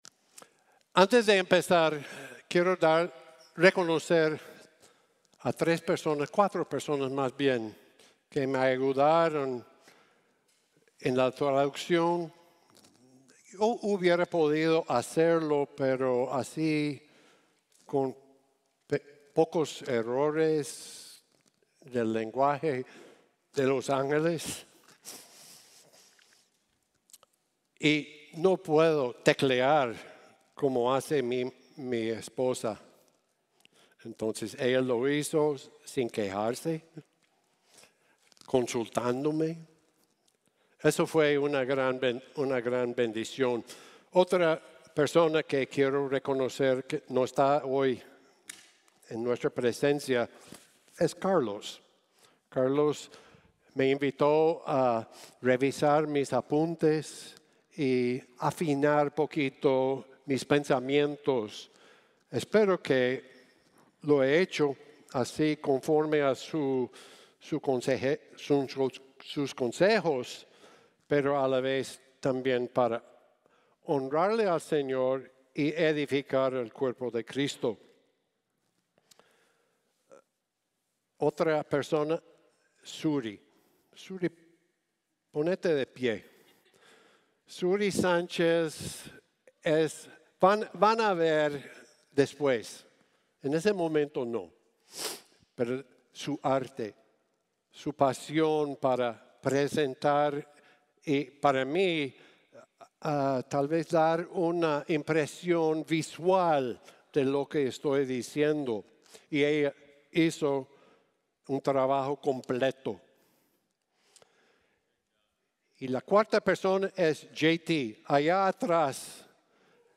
El traje nuevo del emperador | Sermón | Iglesia Bíblica de la Gracia